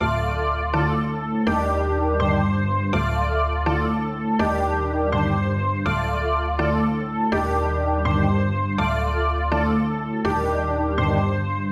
合成器循环
标签： 164 bpm Trap Loops Synth Loops 1.97 MB wav Key : Unknown
声道立体声